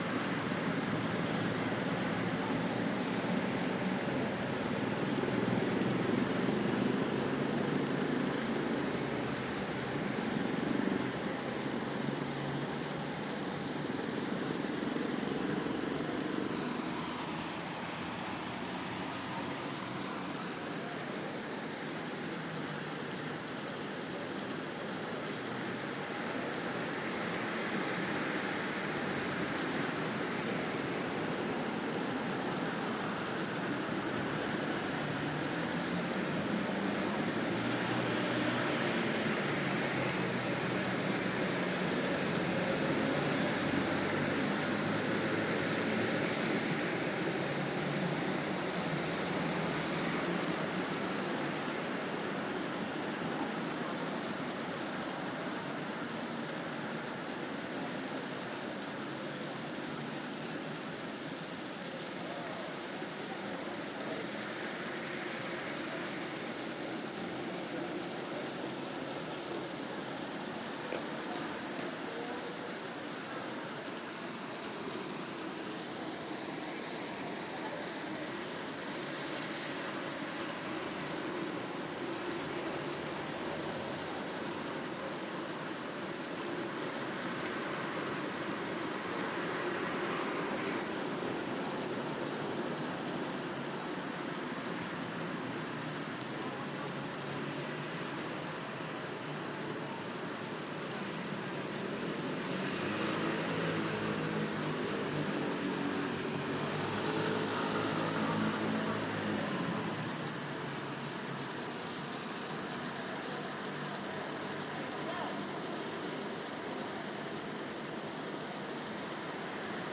两个主要的彩色噪音，一个是自然的，另一个是人工的，主宰着这个地方。全景录音（4楼）来自广场一侧的建筑。
标签： 音景 喷泉 夜晚 交通 噪音 全景 博洛尼亚 广场 现场录音 户外
声道立体声